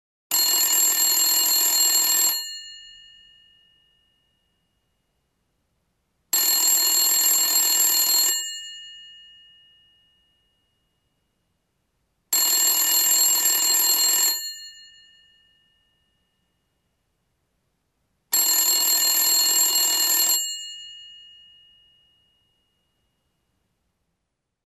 Домашний телефон со шнуром звенит при входящем вызове